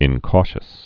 (ĭn-kôshəs)